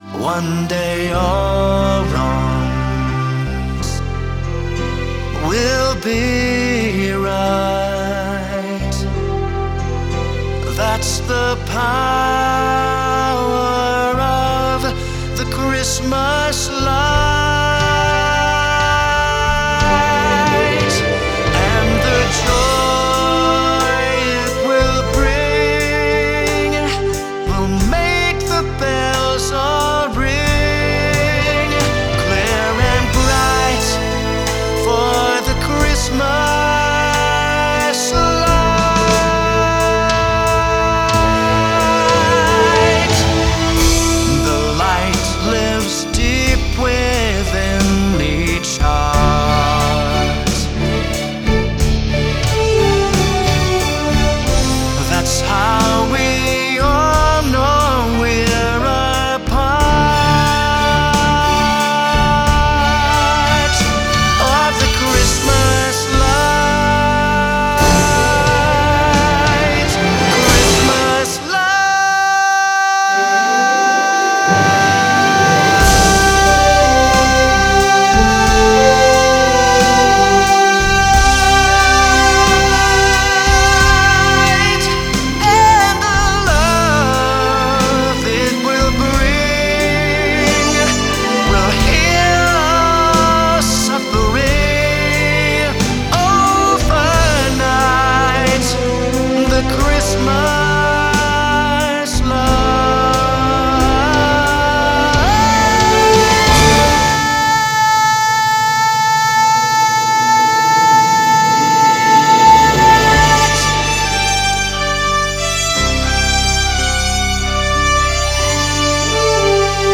Here is a snippet of an upcoming Christmas tune.